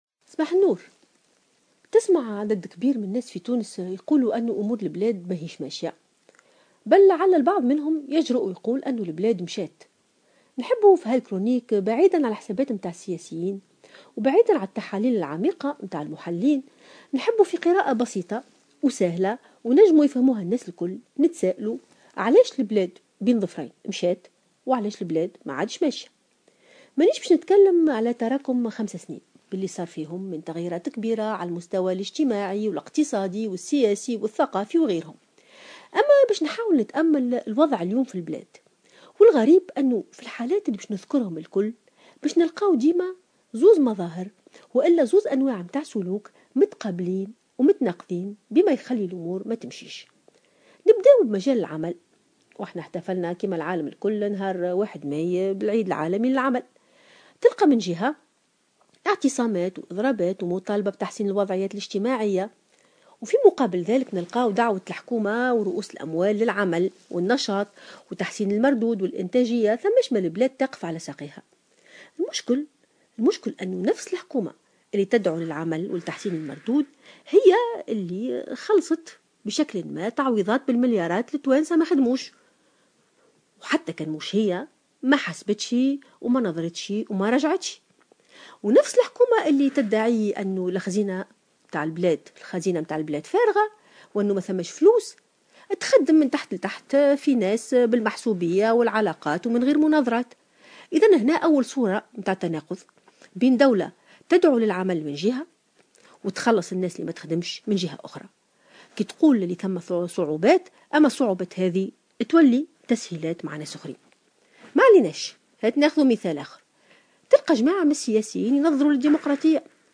تطرقت الأستاذة الجامعية ألفة يوسف في افتتاحية اليوم الإثنين 9 ماي 2016 إلى وضع تونس اليوم المتدهور على المستوى الإقتصادي وأسباب هذا التراجع .